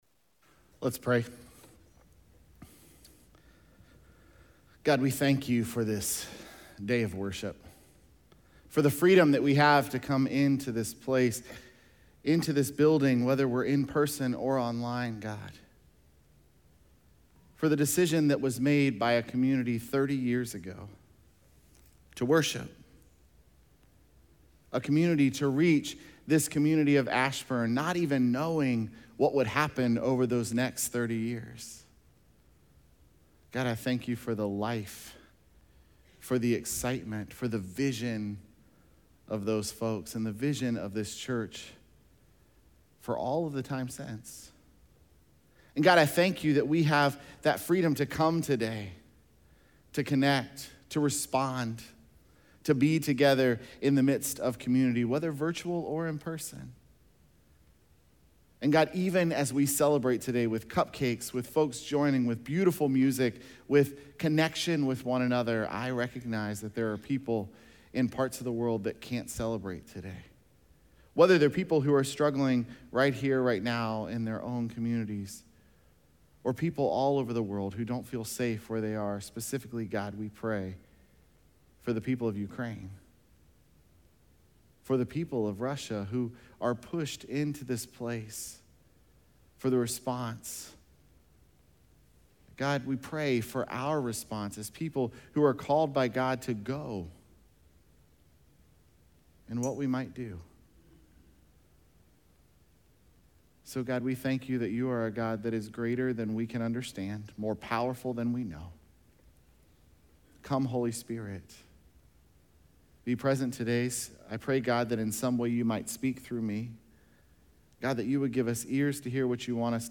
Sunday morning message.